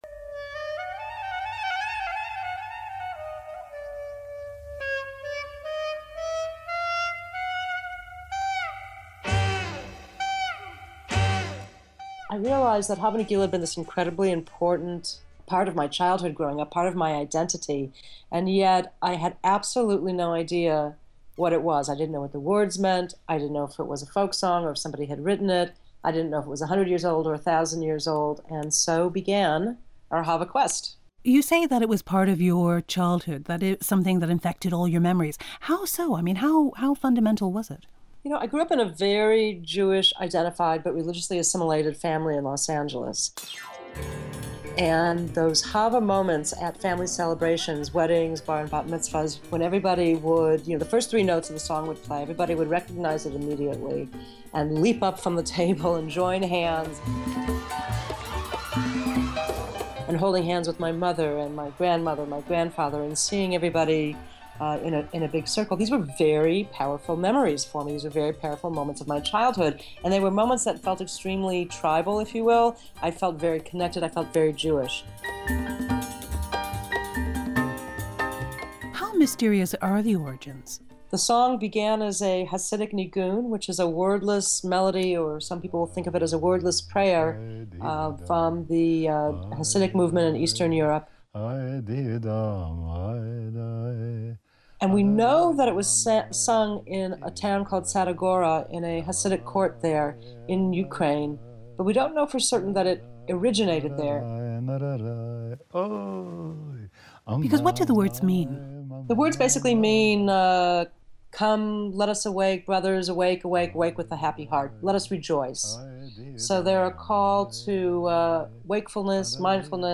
• Type Program: Radio
The StrandBBC World Service ‘The Strand’ radio feature story